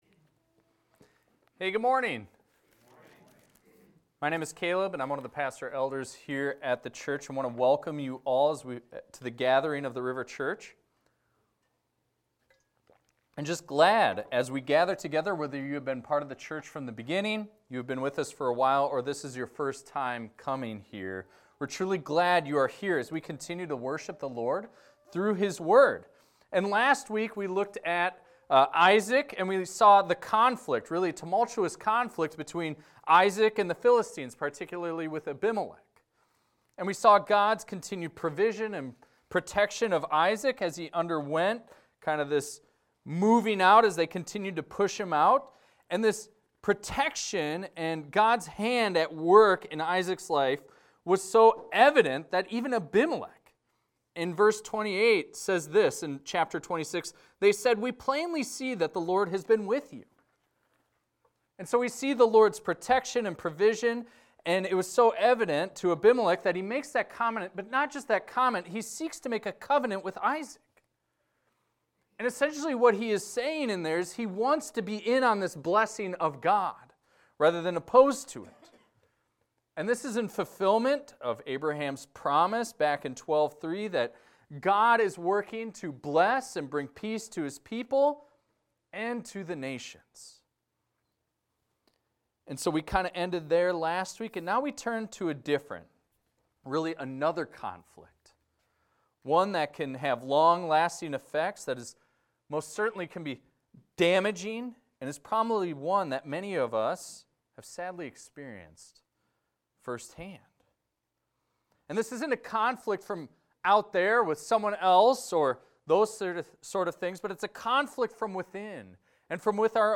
This is a recording of a sermon titled, "Stealing Blessings."